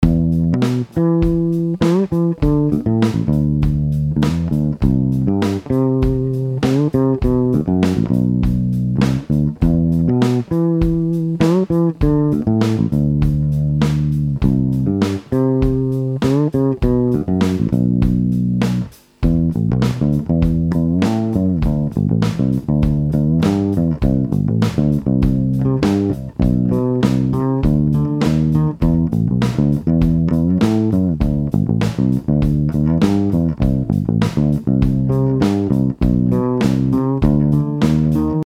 Es ist auch noch ordentlich Rauschen zu hören, ich muß die Tuner doch noch erden.